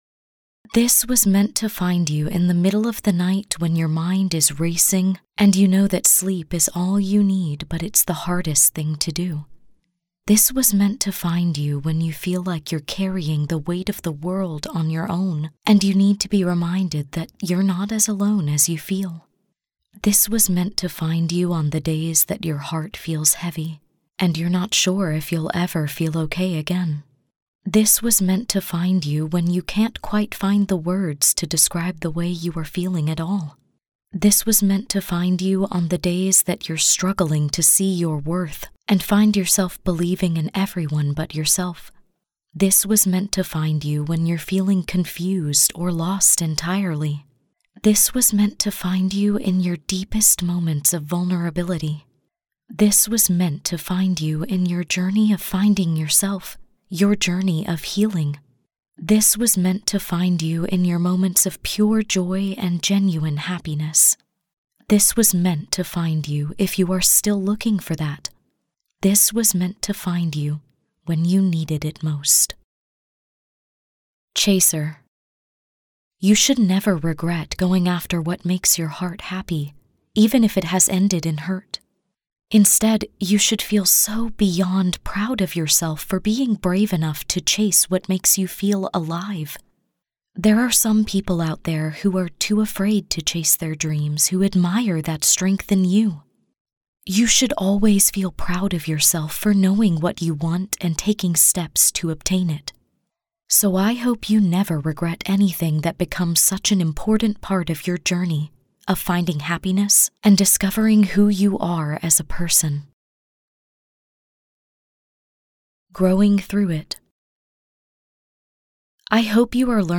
• Audiobook • 2 hrs, 18 mins